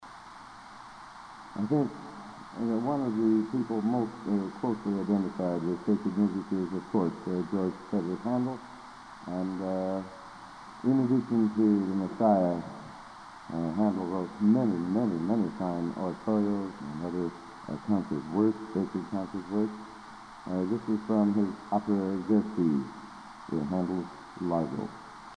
Collection: Broadway Methodist, 1980
Genre: | Type: Director intros, emceeing